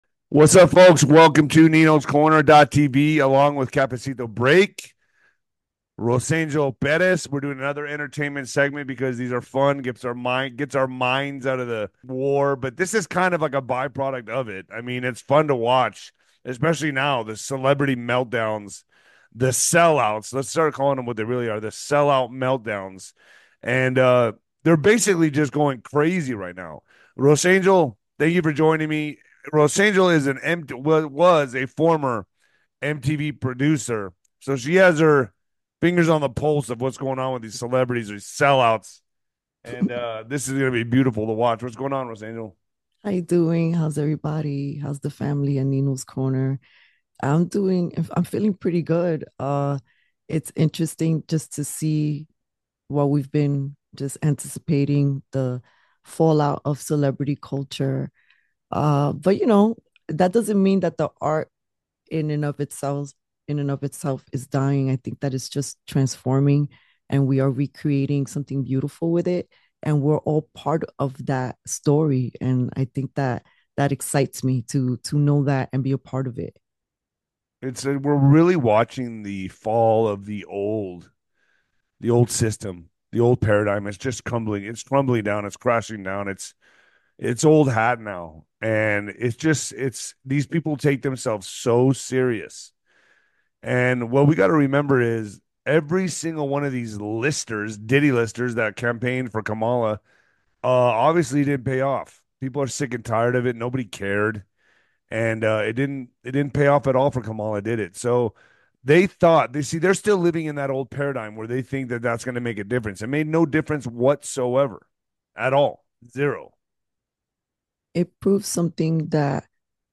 The hosts criticize entertainment industry censorship and discuss a Jaguar ad they find "woke." They also promote a collagen supplement for aging. Tune in for lively debates and insights on today's celebrity world and its impact on society.